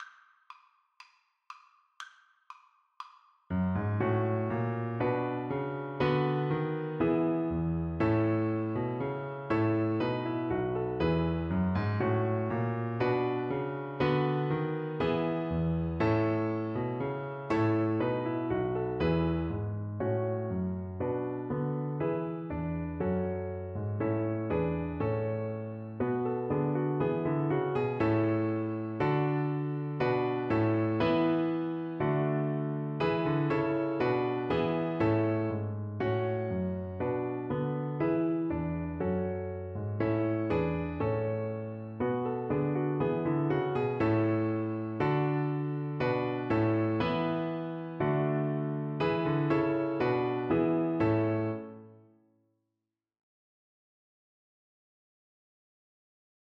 Violin
A major (Sounding Pitch) (View more A major Music for Violin )
2/2 (View more 2/2 Music)
Allegro (View more music marked Allegro)
Traditional (View more Traditional Violin Music)
the_haunted_castle_VLN_kar1.mp3